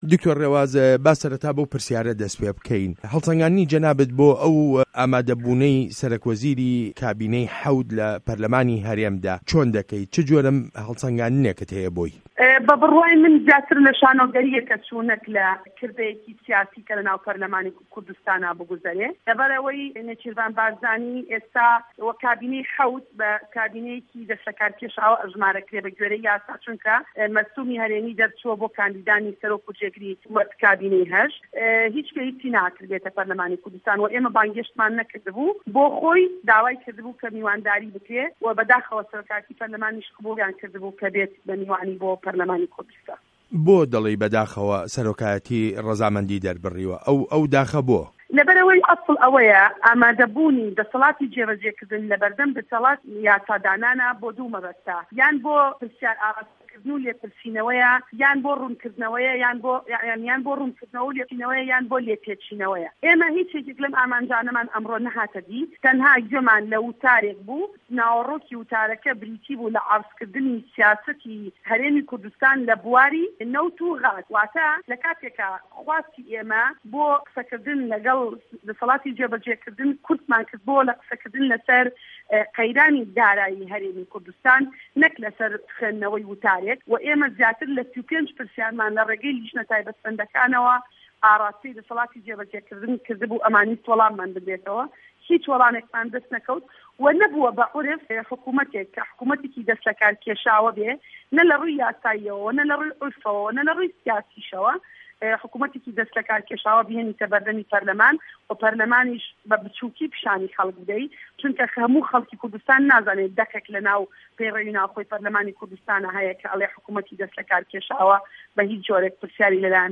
وتووێژ له‌گه‌ڵ دکتۆر ڕێواز فایه‌ق